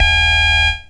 L_ABEEP.mp3